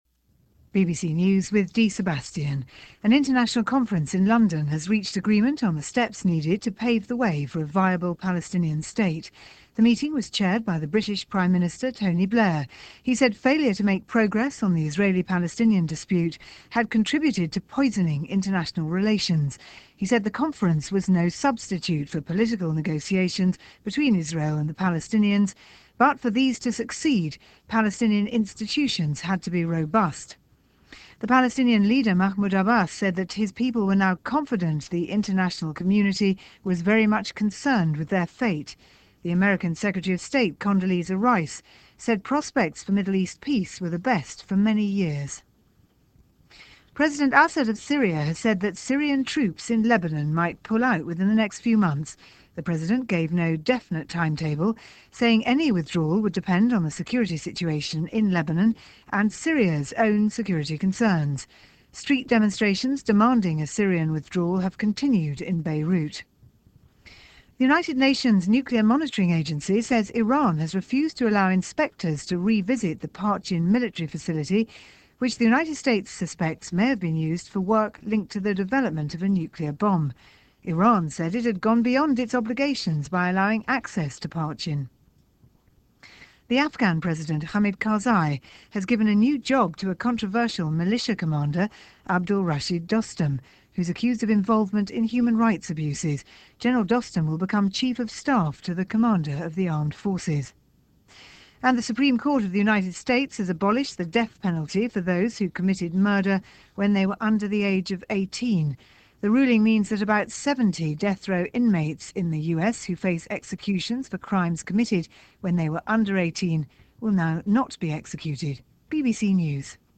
News-for-March-1-2005-BBC.mp3